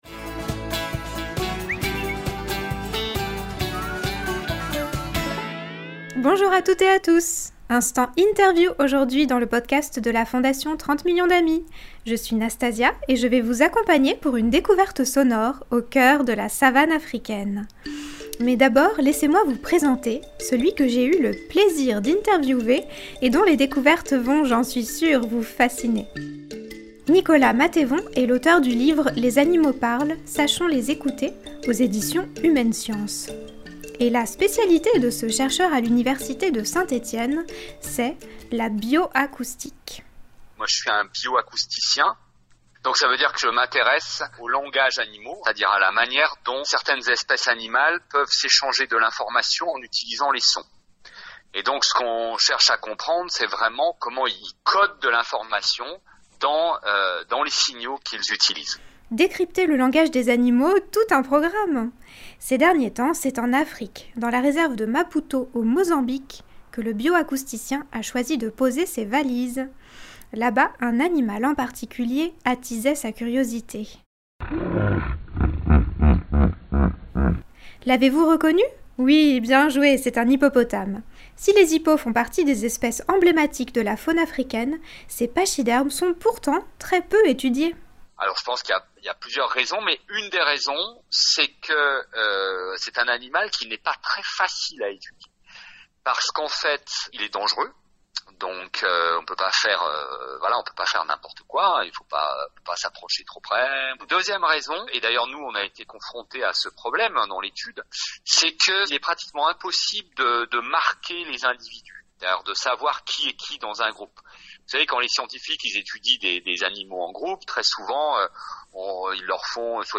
Rencontre.